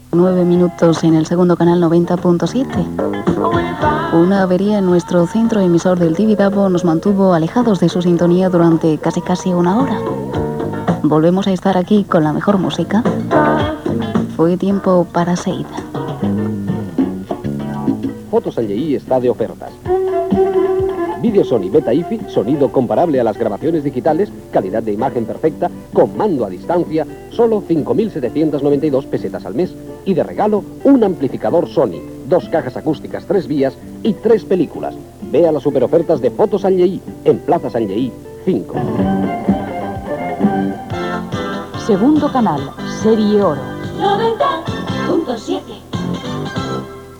Hora, identificació, retorn a l'emissió després d'una avaria, publicitat, indicatiu com "Segundo canal serie oro"
FM